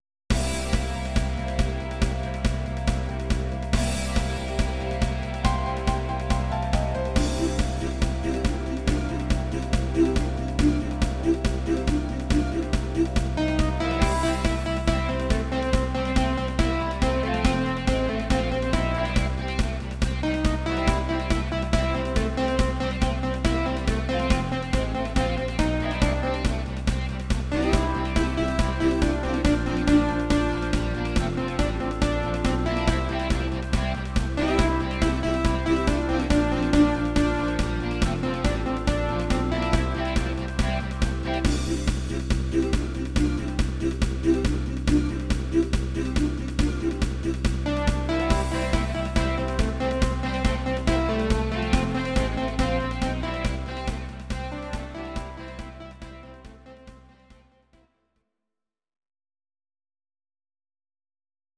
Audio Recordings based on Midi-files
Pop, German, 1990s